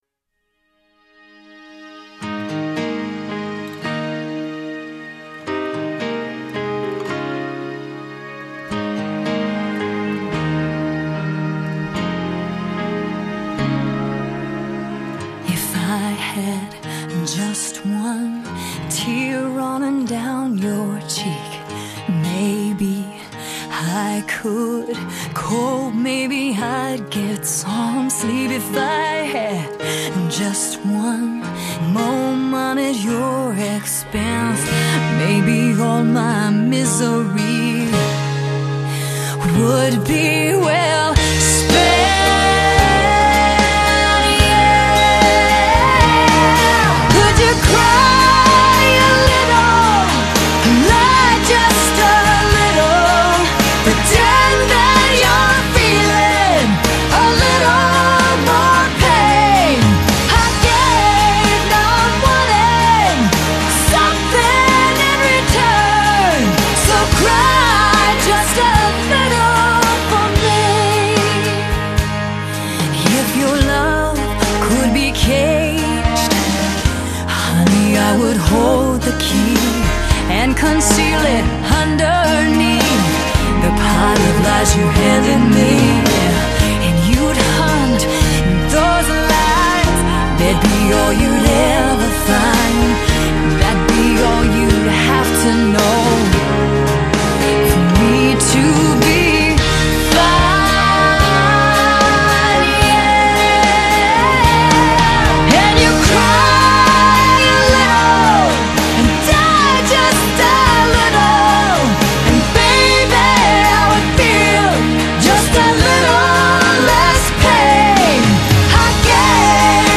一方面结合乡村、摇滚、节奏蓝调与流行的新 鲜风情